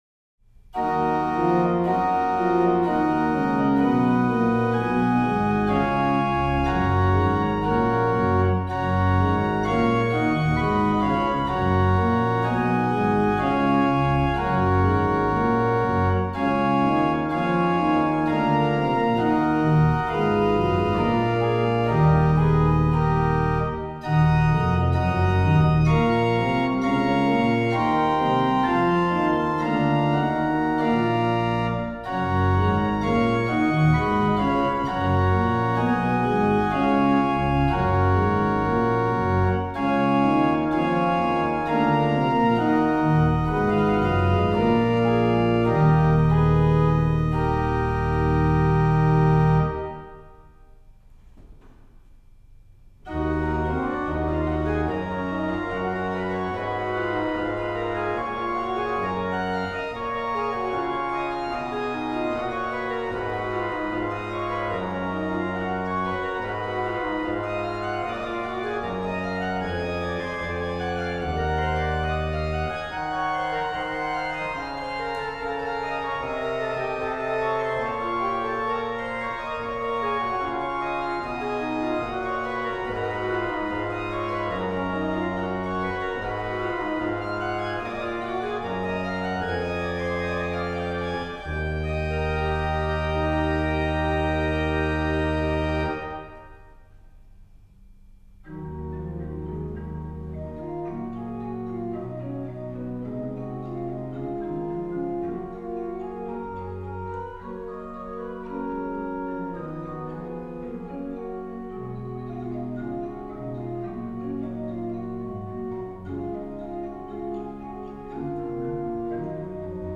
Subtitle   [Choral]
lh: MAN: Qnt16, Pr8, Viol8, Oct4
MAN: Viol8, Bor8, Oct4
PED: Tr8
MAN: Pr8, Bor8, Oct4, Qnt3, Oct2, 1 3/5
PED: Sub16, Viol16, Oct8, Tr8